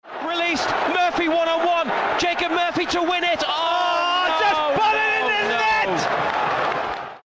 just put it in the net Meme Sound Effect
Category: Sports Soundboard